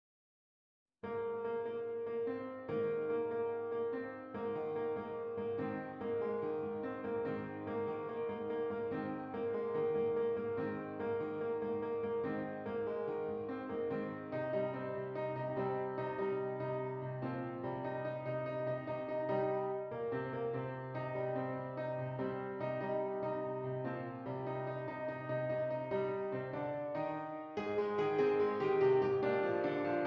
Does Not Contain Lyrics
A Flat Major
Moderately Fast